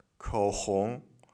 口音（男声）